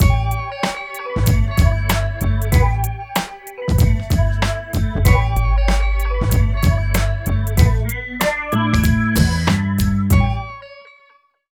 43 LOOP   -L.wav